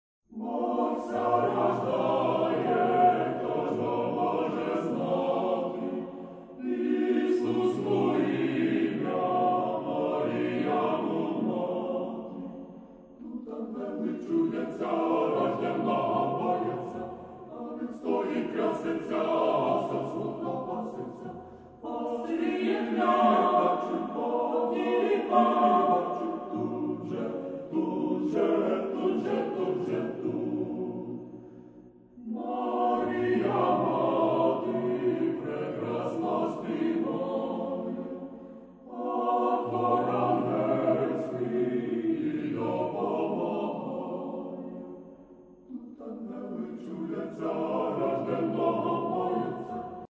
Christmas  (94)